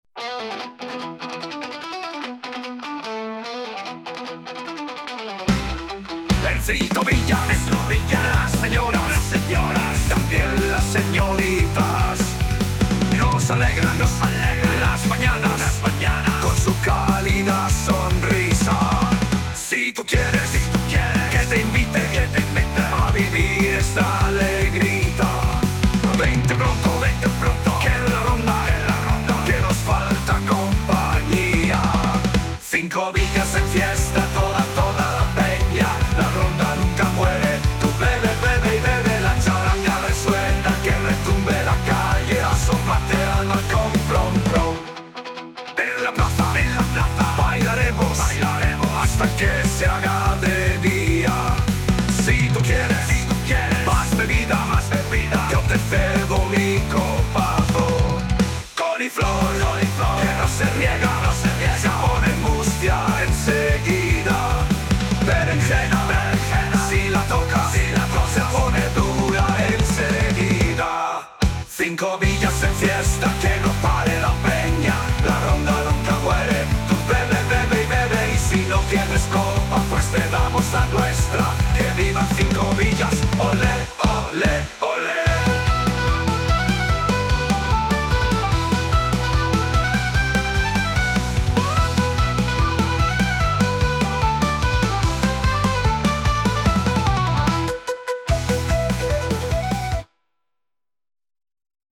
Las fiestas de Cincovillas 2025
Escucha la canción de este año: Ronda de mozos 2025